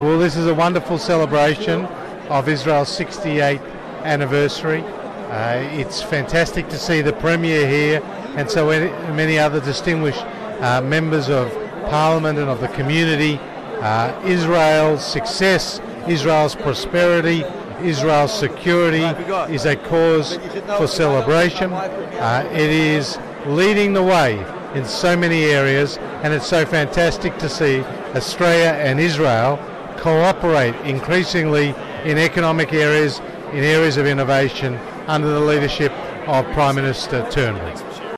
Australian Jews celebrate in Sydney Israel's 68th Independence Day, with Hon Josh Frydenberg MP